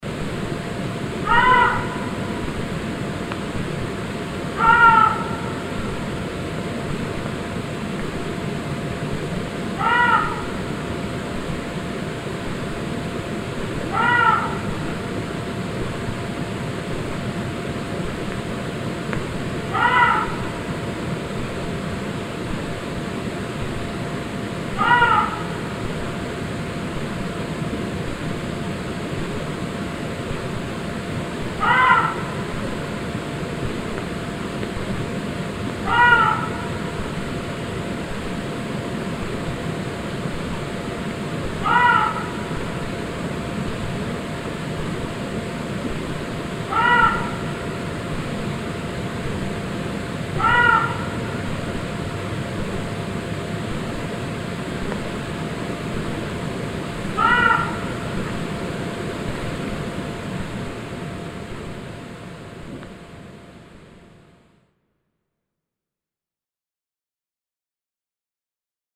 Here are the sounds of the muntjac deer and at this site you will find just about every outdoor sound created in the UK.